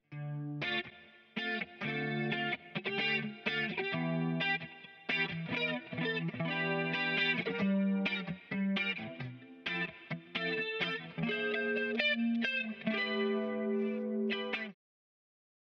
Αναλογική αισθητική στην ψηφιακή εποχή: Συγκριτική μελέτη αναλογικών και ψηφιακών τεχνικών στην ηχογράφηση και μίξη της ηλεκτρικής κιθάρας